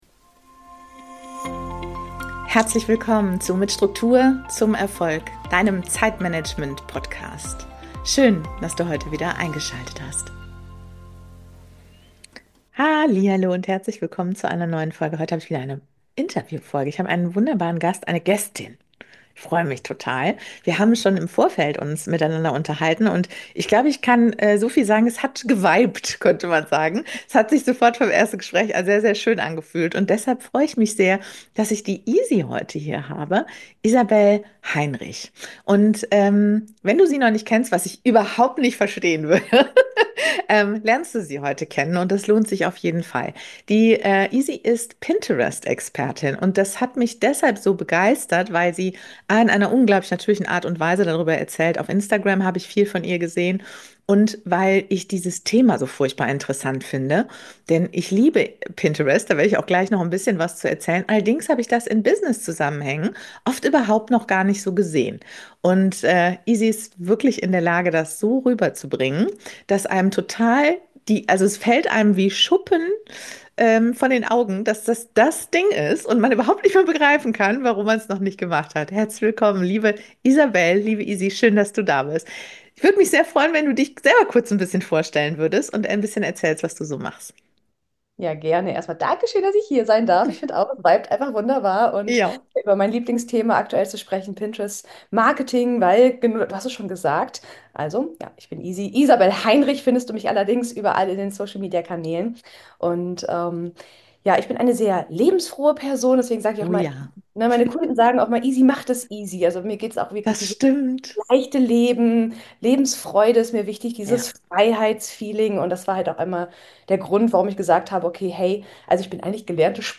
#114 - Pinterest statt Dauerstress – wie leises Marketing langfristig wirkt (Interview